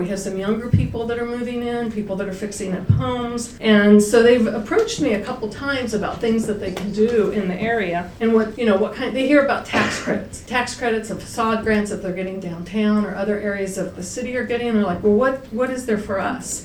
The Cumberland Mayor and City Council was asked to consider including the Dumbhundred district in the National Historic Register during last night's public meeting.